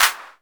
Index of /musicradar/essential-drumkit-samples/Vintage Drumbox Kit
Vintage Clap 01.wav